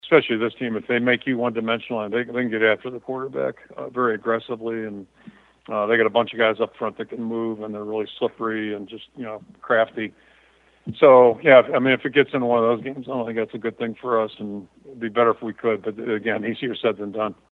That’s Iowa coach Kirk Ferentz.